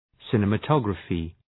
Shkrimi fonetik{,sınımə’tɒgrəfı}